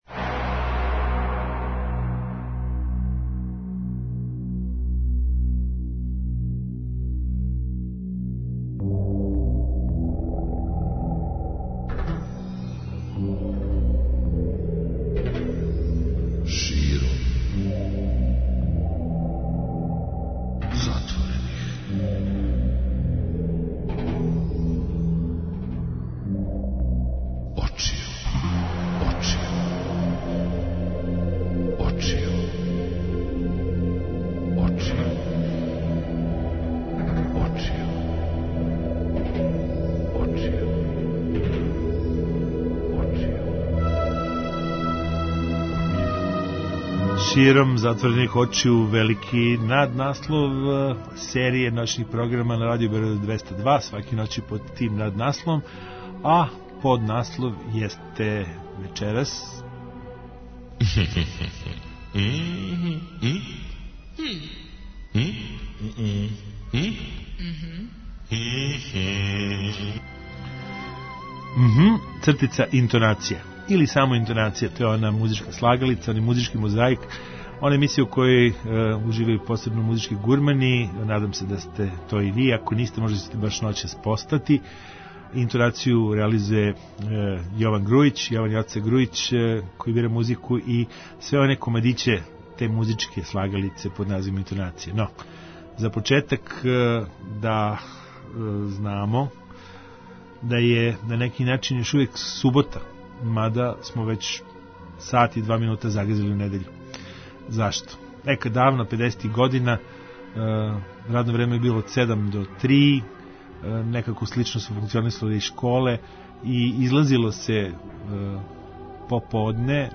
Реч је о "музичком мозаику" састављеном пре свега на бази асоцијација на најлепше детаље из рокенрол и поп музике.